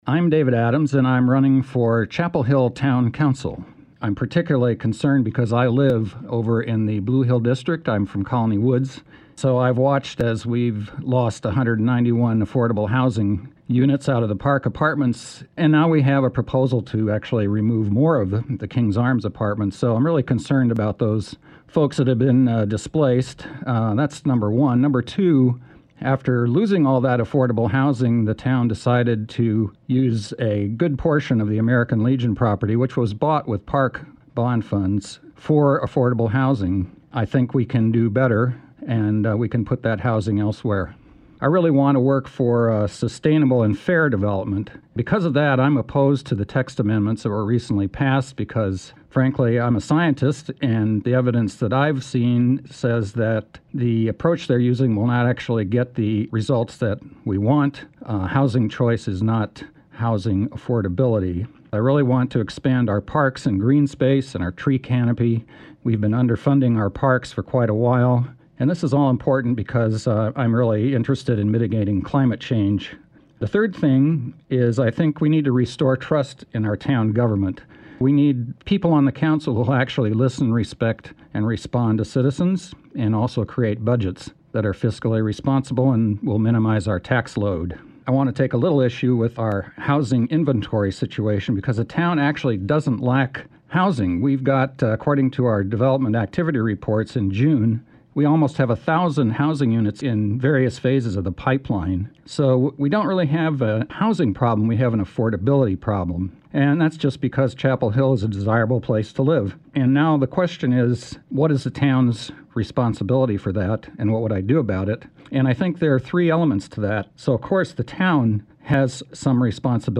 During this local election season, 97.9 The Hill and Chapelboro spoke with candidates for races representing Chapel Hill, Carrboro and Hillsborough.
Their answers (lightly edited for clarity and brevity) are shared here, as well as links to their respective campaign websites or pages.